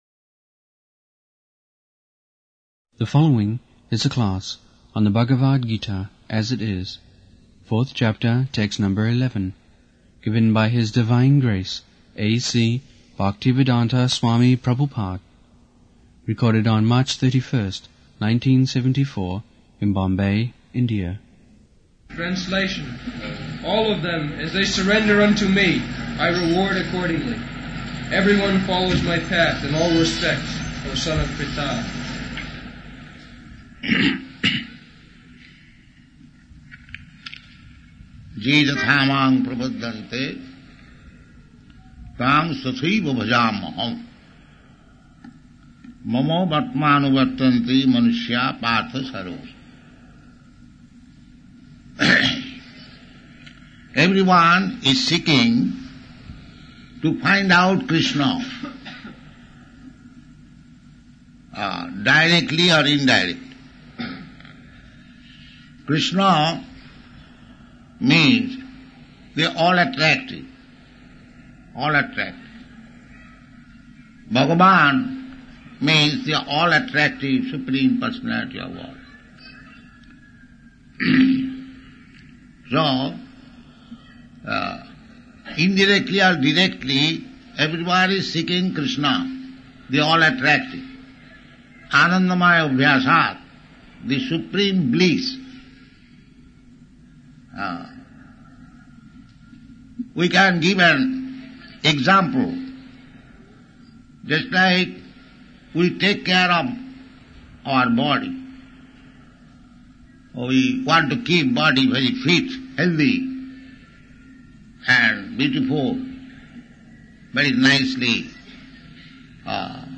74/03/31 Bombay, Bhagavad-gita 4.11